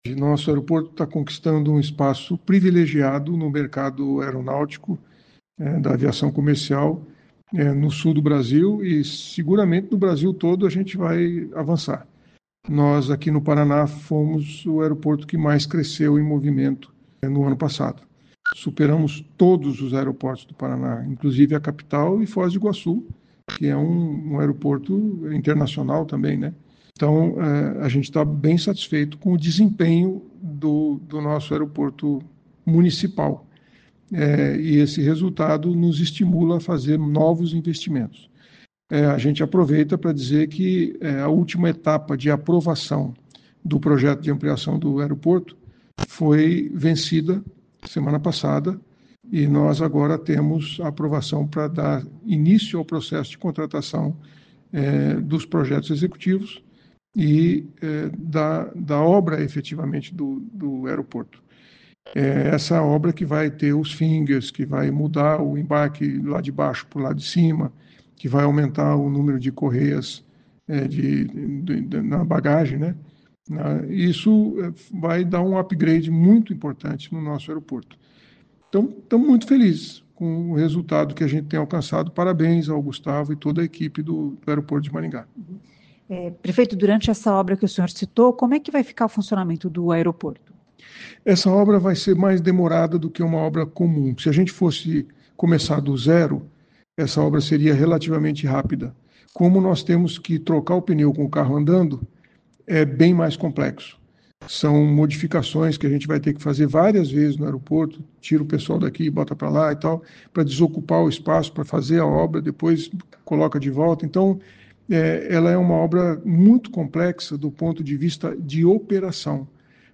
Na semana passada a Prefeitura de Maringá obteve a aprovação do projeto de ampliação do aeroporto e já pode contratar os projetos executivos, diz o prefeito Silvio Barros. O prefeito explica como será o funcionamento do aeroporto durante a obra. Silvio Barros também comenta o aumento de passageiros no terminal aéreo.